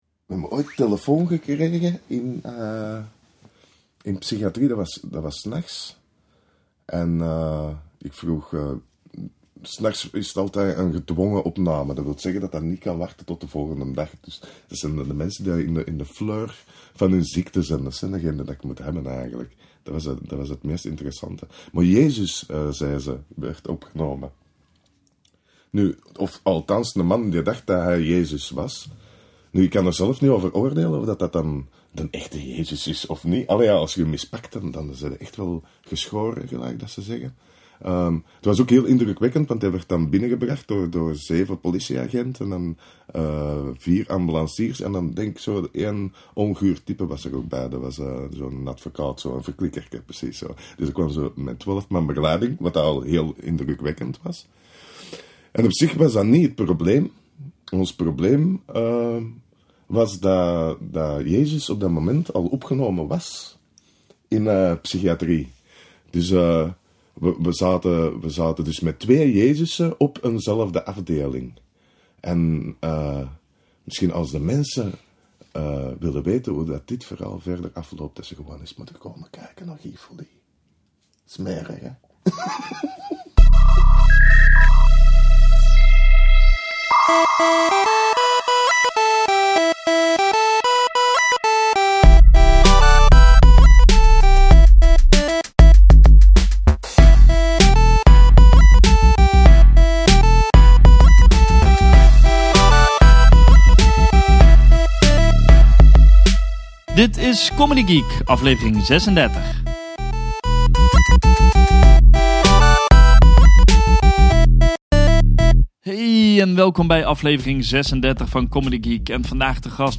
In deze podcast geen interviews, maar openhartige en eerlijke gesprekken aan de keukentafel bij de artiest thuis.
Een lachvol gesprek vol diepgang!